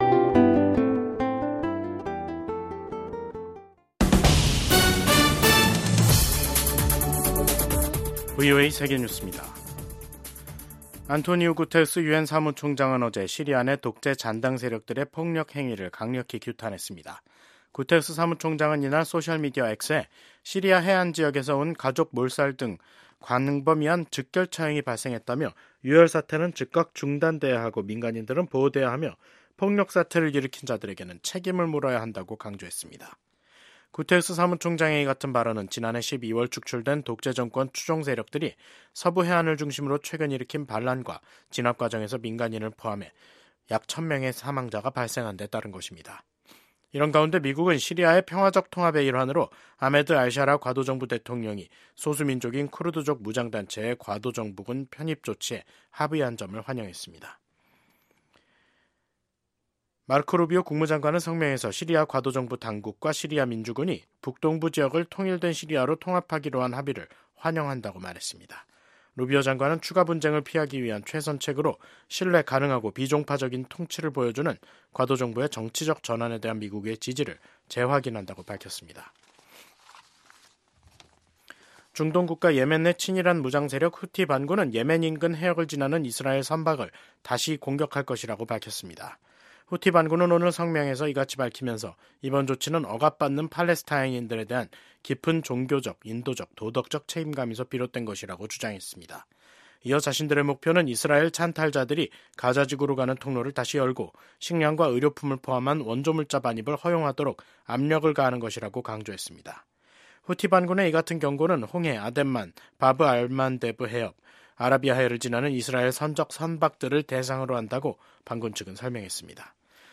VOA 한국어 간판 뉴스 프로그램 '뉴스 투데이', 2025년 3월 12일 3부 방송입니다. 북한의 우크라이나전 개입 중단이 도널드 트럼프 미국 행정부의 요구라고 조셉 윤 한국 주재 미국 대사대리가 밝혔습니다. 미국 정부가 북한이 도널드 트럼프 대통령 취임 이후 처음으로 탄도미사일을 발사한 것을 강력히 규탄했습니다.